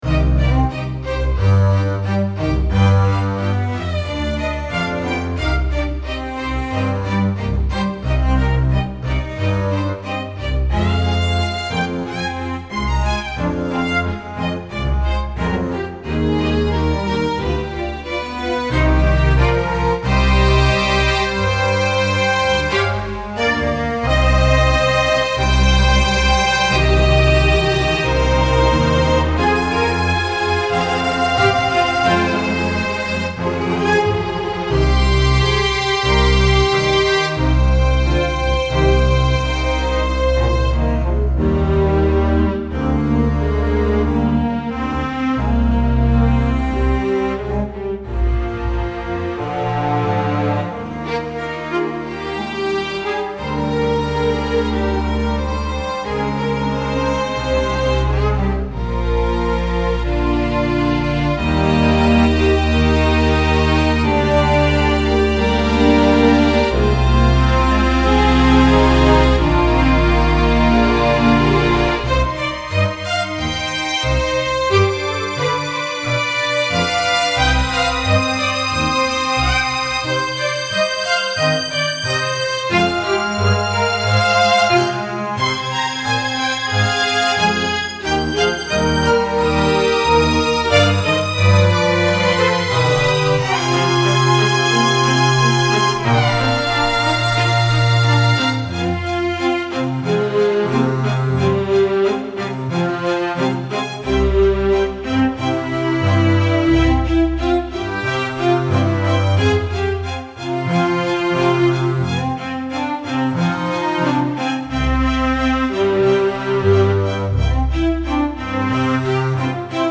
This string quintet reflects the energy and conflict of economic struggle and perseverance.
String-Quintet-1-Utica.mp3